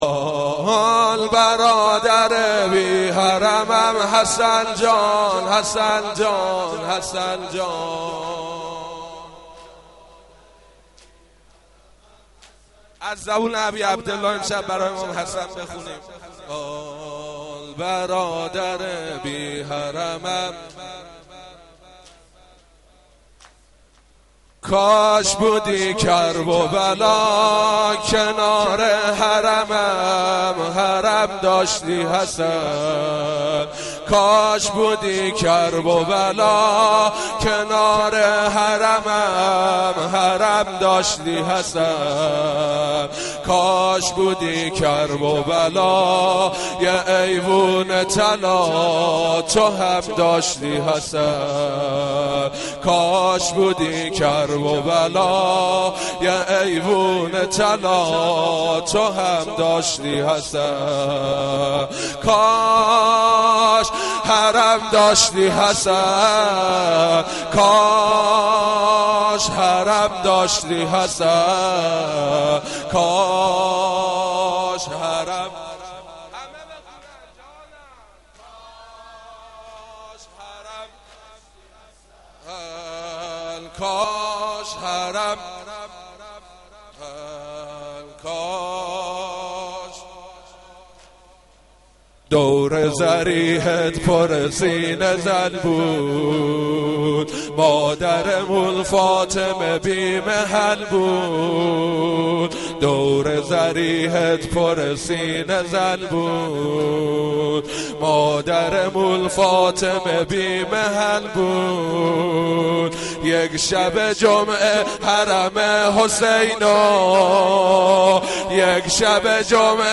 دانلود مداحی امام حسن